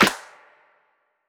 HFMSnare4.wav